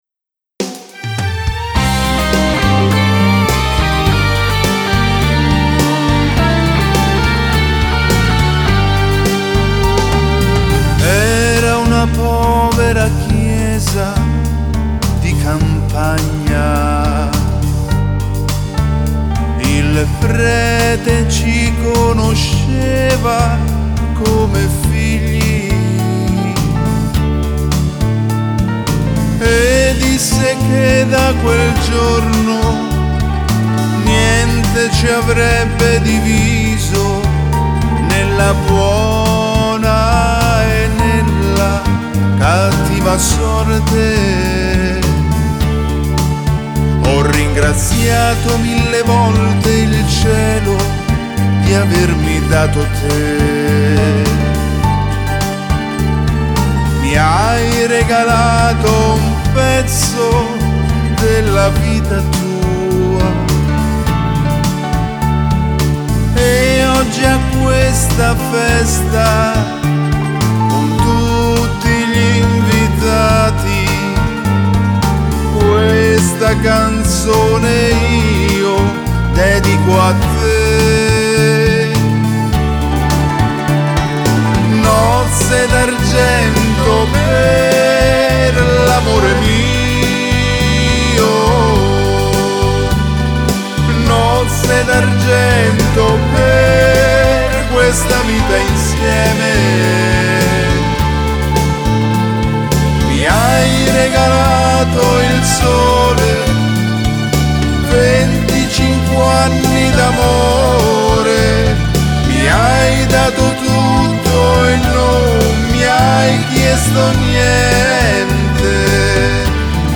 Beguine